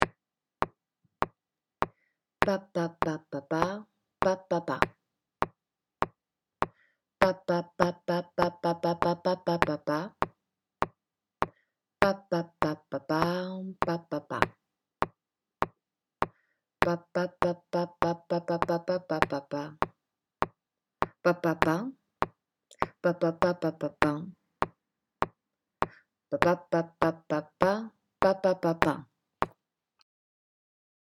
Rythmique à travailler strophe :
comme-ci-comme-c3a7a-papa-strophe1.mp3